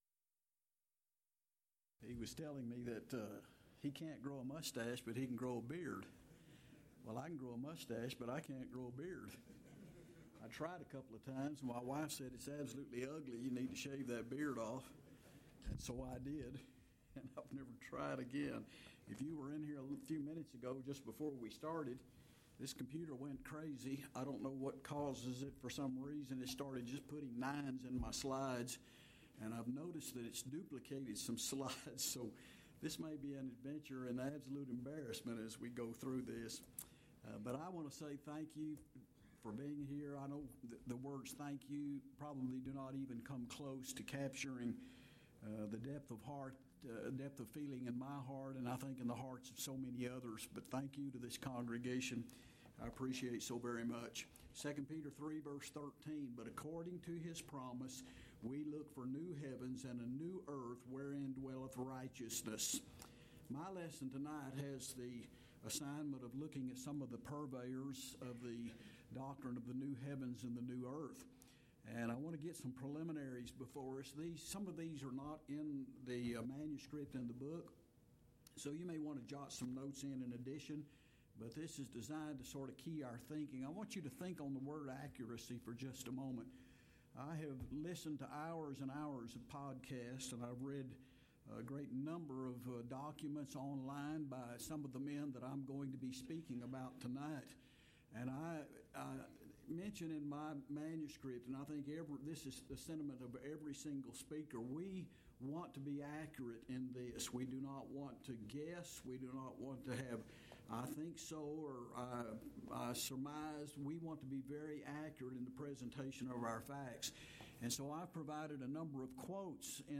Alternate File Link File Details: Series: Lubbock Lectures Event: 23rd Annual Lubbock Lectures Theme/Title: A New Heaven and a New Earth: Will Heaven Be On A "New Renovated" Earth?
If you would like to order audio or video copies of this lecture, please contact our office and reference asset: 2021Lubbock13 Report Problems